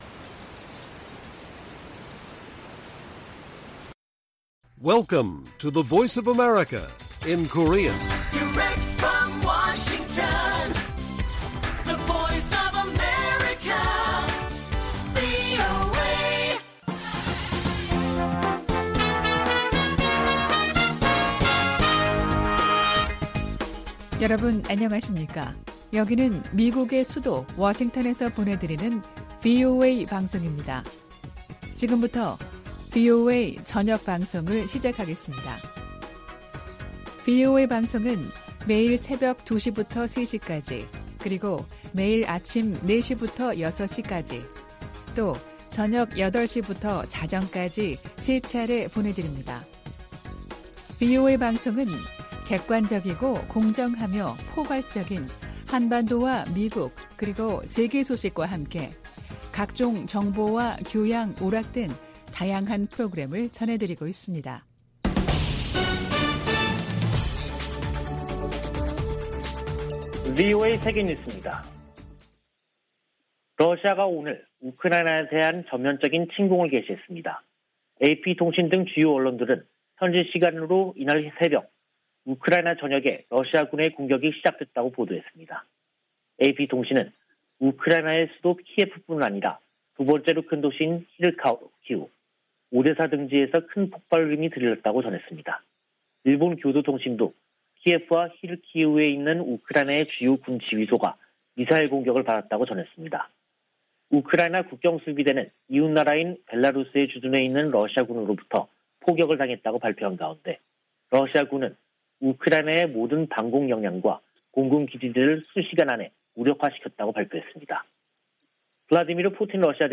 VOA 한국어 간판 뉴스 프로그램 '뉴스 투데이', 2022년 2월 24일 1부 방송입니다. 러시아의 우크라이나 침공으로 미-러 갈등이 격화되고 있는 가운데 북한의 외교 셈법이 복잡해졌다는 분석이 나오고 있습니다. 미 국방부는 우크라이나에 대한 한국의 지지 성명에 주목했다고 밝혔습니다. 미국의 전통적 대북 접근법으로는 북한 문제를 해결하는 데 한계가 있으며, 대통령의 리더십이 중요하다는 보고서가 나왔습니다.